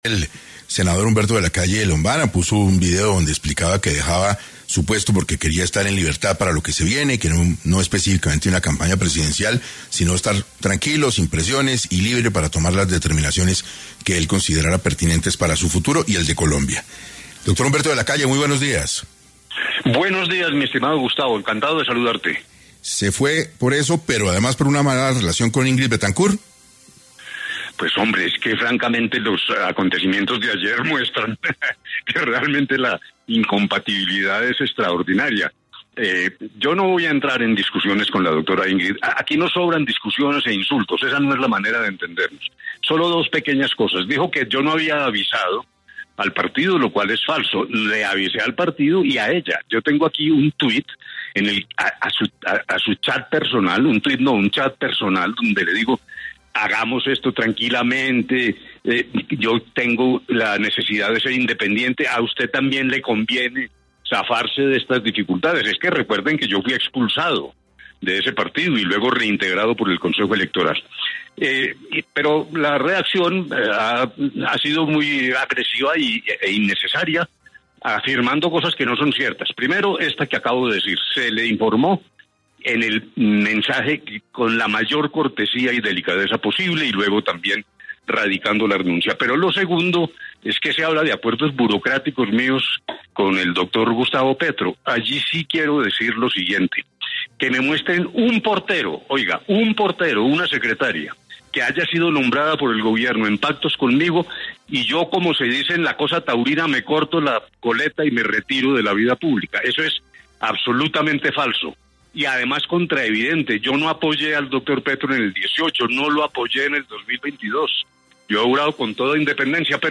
En 6AM Humberto De La Calle, exsenador de Colombia, señaló las razones de su renuncia y Ingrid Betancourt, directora del Partido Verde Oxígeno dio su opinión al respecto
Humberto de La Calle estuvo en 6AM de Caracol Radio, en donde explicó su relación con Betancourt y las razones que lo llevaron a tomar la decisión de renunciar al Senado.